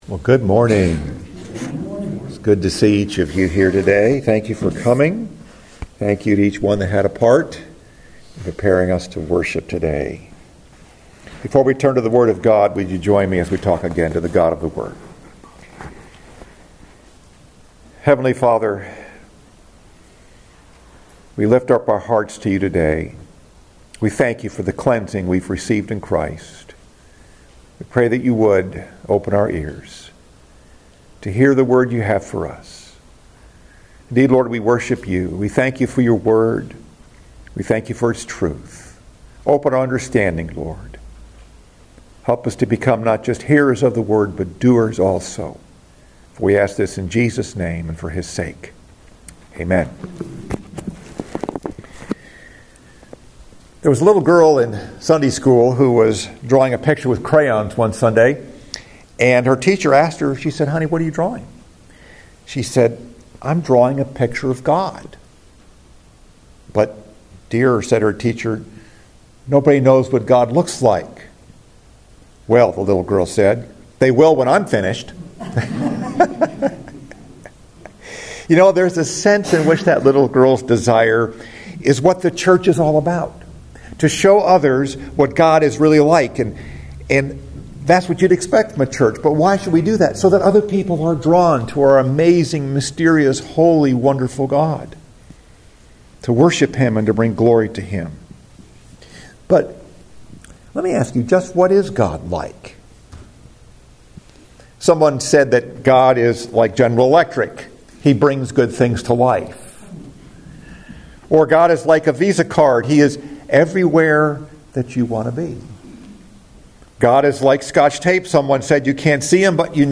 Message: “Mysterious God” Scripture: Genesis 1:26